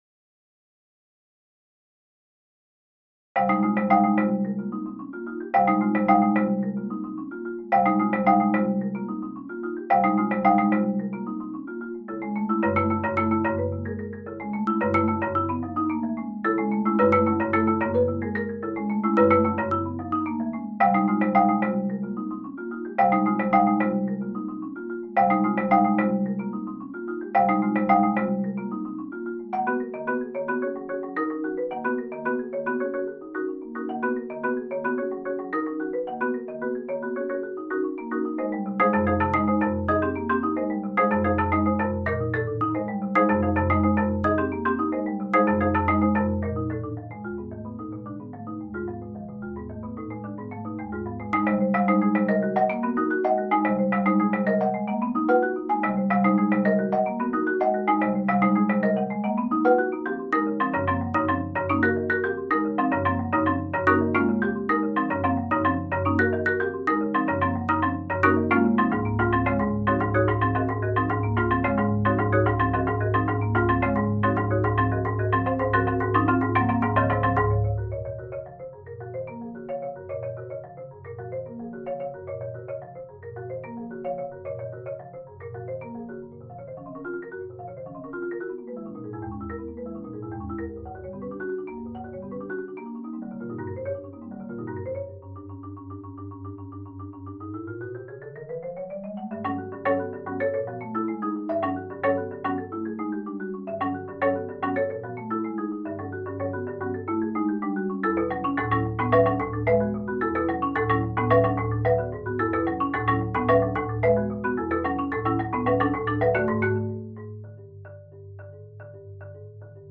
Genre: Duet for 2 Percussion
# of Players: 2
Two 5-octave Marimbas